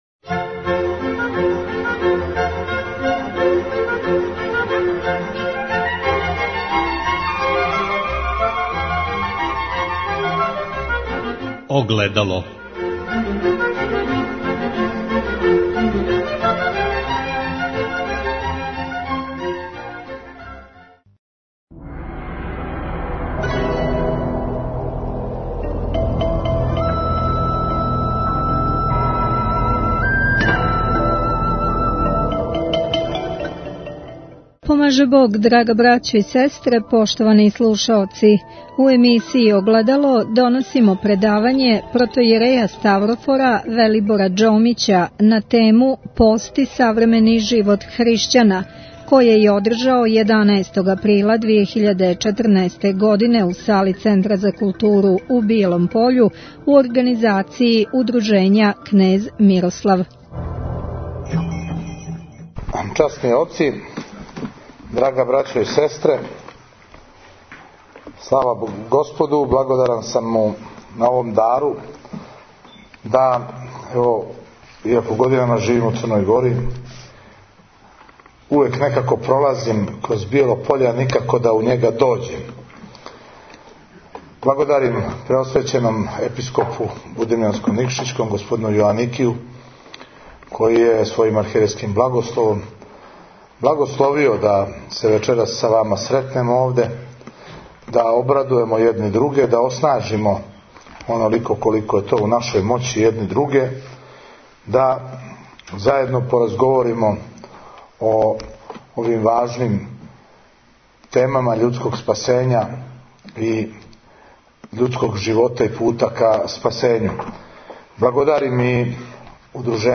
Предавање
у сали Центра за културу у Бијелом Пољу